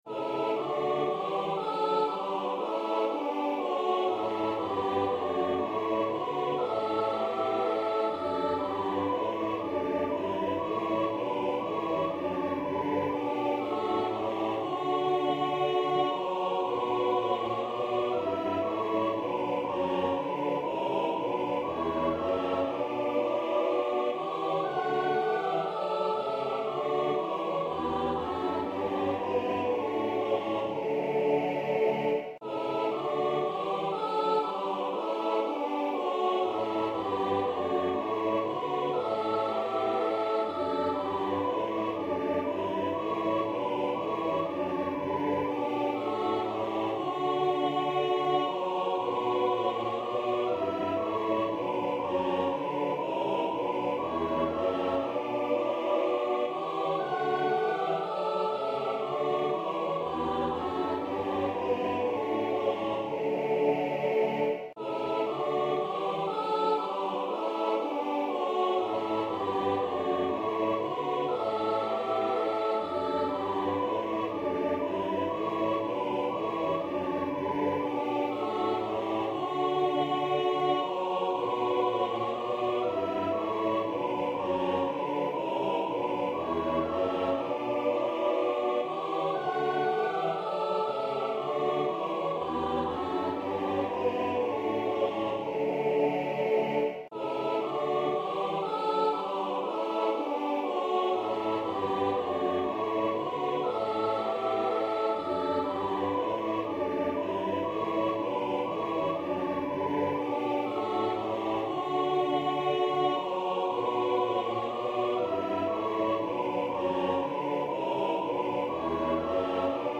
I think I started out in G major, but abandoned it pretty quickly to D major, primarily for range considerations based on how I wanted to write the tune.
I noticed that lines 5 and 6 of the vast majority of the verses were darker in content, so I dabbled in the relative minor (b minor) there.
I was also able to export audio files from Finale.  I saved the hymn as piano, string quartet, pipe organ, and choir (midi).
alto, soprano) is isolated in the left channel so people can learn their part easier by adjusting the balance setting on the playback device (or removing the right earphone).
JCS_Alto.mp3